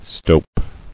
[stope]